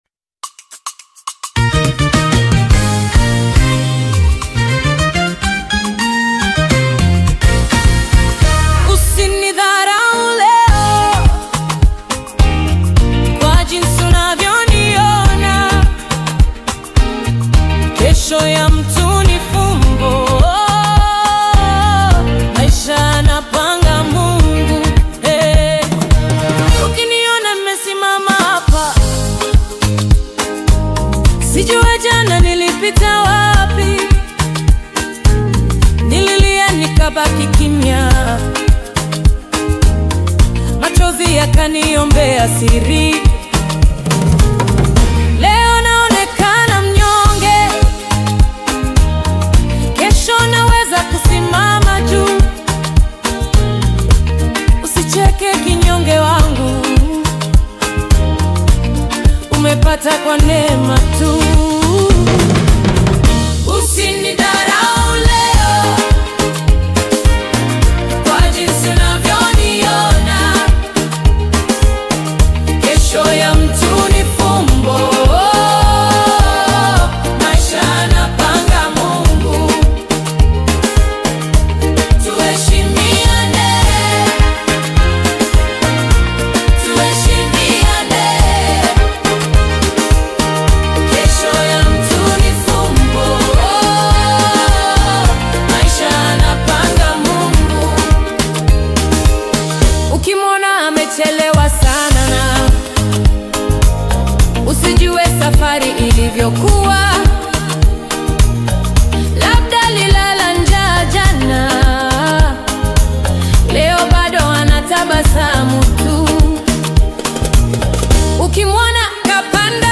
AudioGospel
Afro-Beat